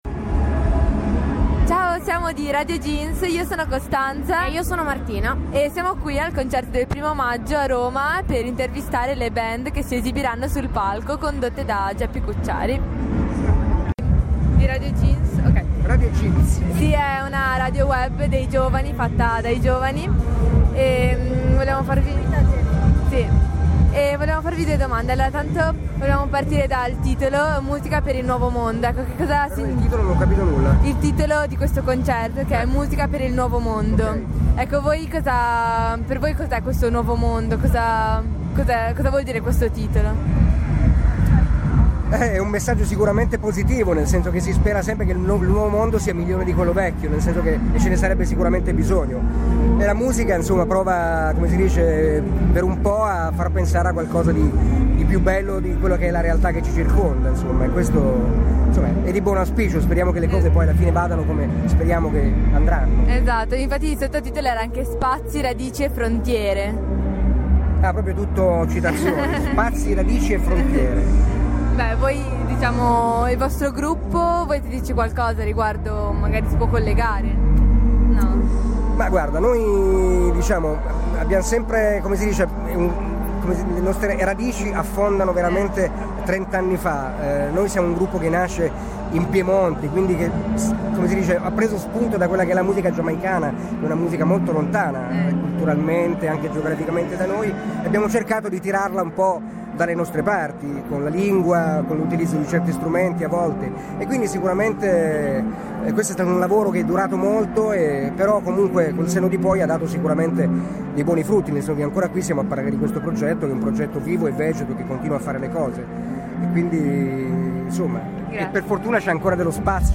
play_circle_filled Intervista a Africa Unite (Concerto del I° Maggio - Roma) Radioweb C.A.G. di Rapallo Band musicale intervista del 01/05/2013 Intervista approfondita con lo storico gruppo di Reagge italiano al concerto del I° Maggio a Roma. Dopo 13 album pubblicati ed un'attività trentennale gli Africa Unite parlano ai microfoni della nostra TWR raccontando qualcosa della loro vicenda musicale ed umana riflettendo su diversi temi, tra cui la condizione nell'oggi per le giovani band e per i giovani in generale.